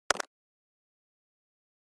snd_click.ogg